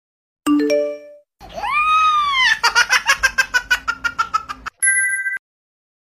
Genre: Nada dering imut Tag